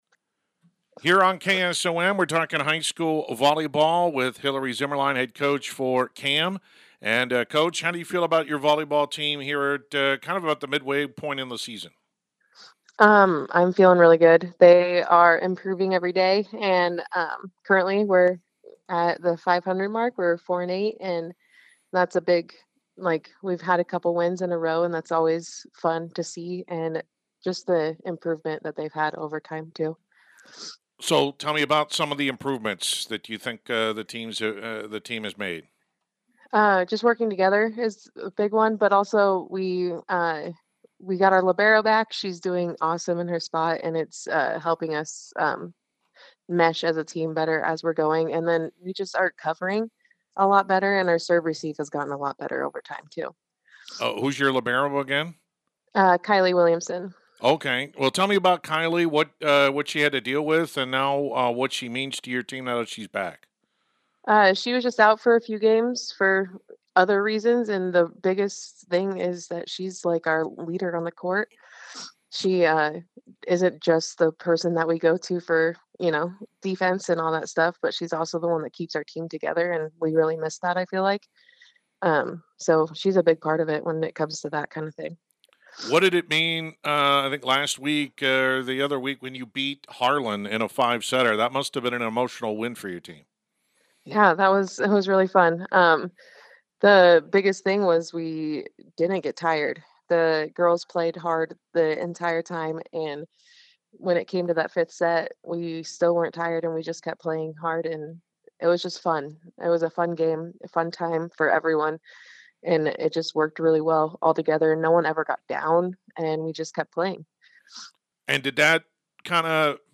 Complete Interview
cam-volleyball-9-24.mp3